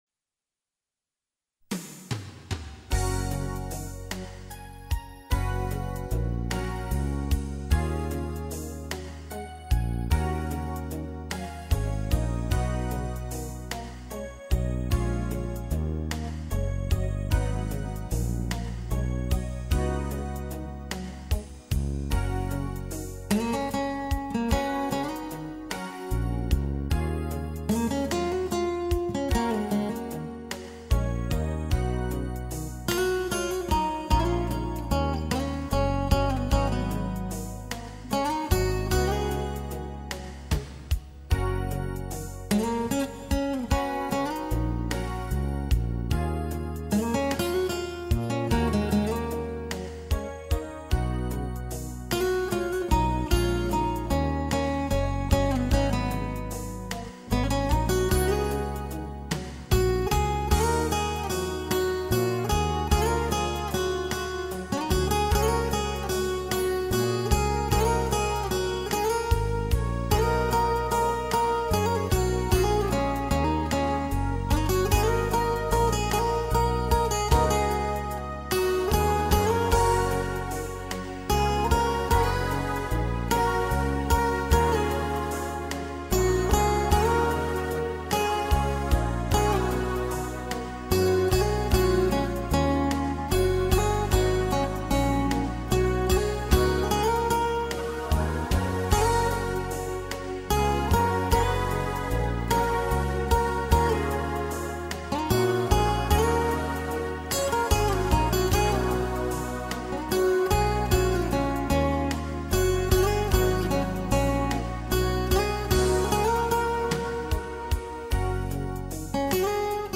موسیقی کنار تو
سبک موسیقی بی کلام